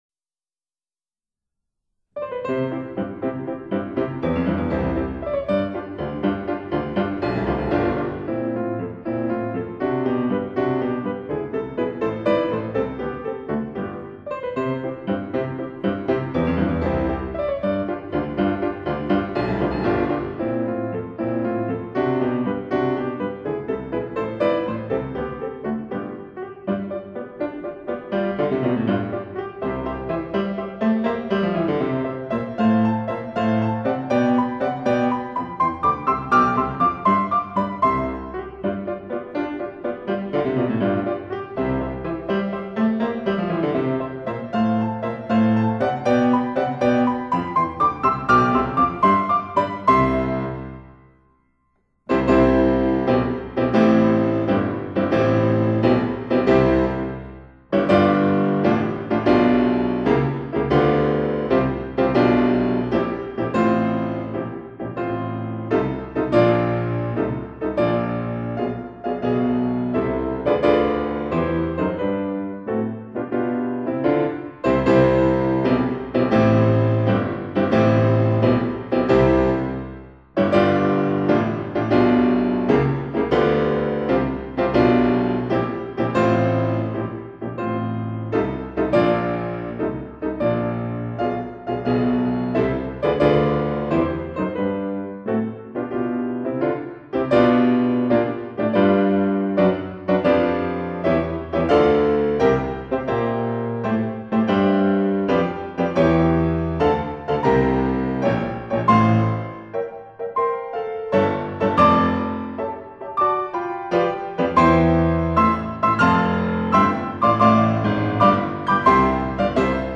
音樂類型：CD古典管弦樂